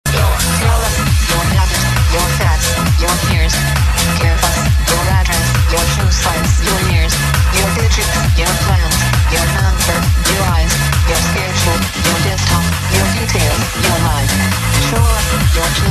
But what's the name of the house track in the background?